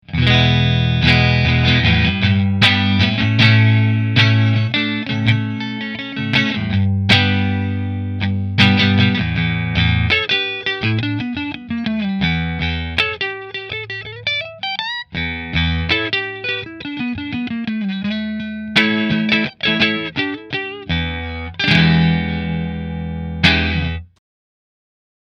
Back is made of Sassafrass which is similar to alder in tone.
No shrill high end. A real smooth guitar!
Pickups – Rio Grande Vintage Tallboys in neck and middle position.
Rahan Guitars RP Single Cutaway Ambrosia Position 1 Through Fender